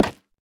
Minecraft Version Minecraft Version 1.21.5 Latest Release | Latest Snapshot 1.21.5 / assets / minecraft / sounds / block / nether_wood_trapdoor / toggle1.ogg Compare With Compare With Latest Release | Latest Snapshot